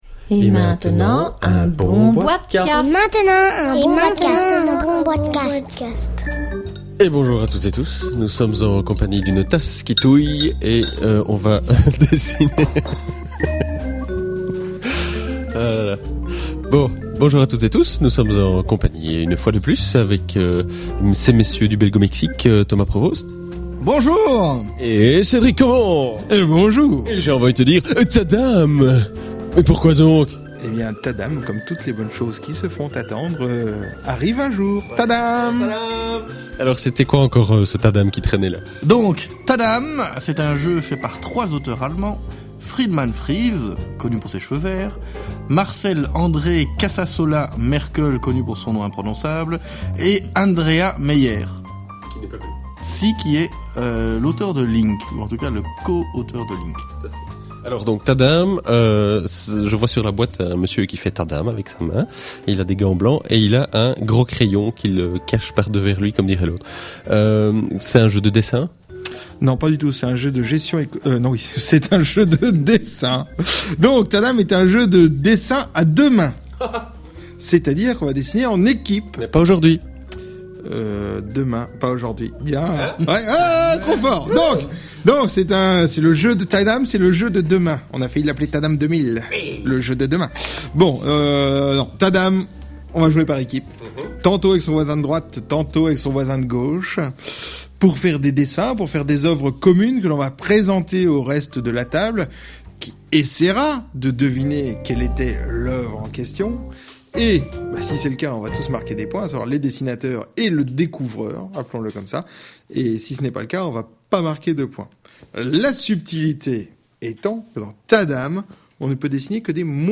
enregistré lors du salon international de la Nuremberg Toy Fair 2010